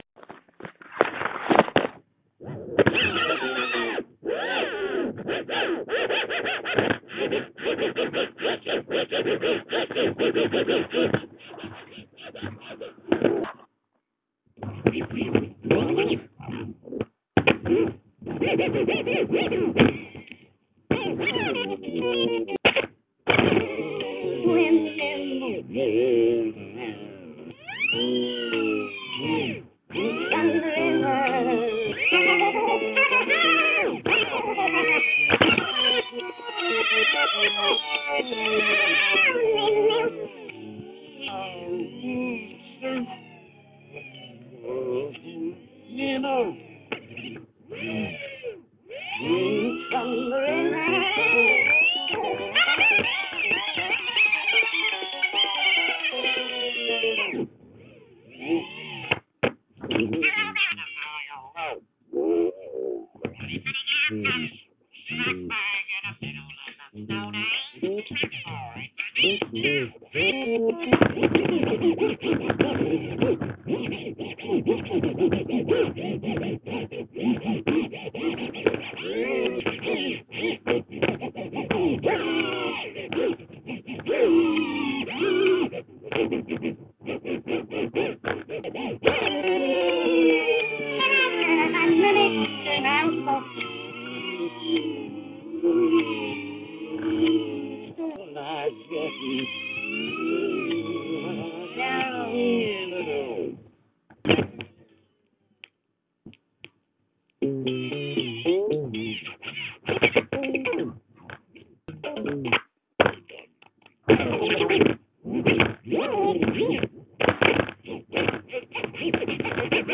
我不是一个DJ，但我在立体声中刮了一张唱片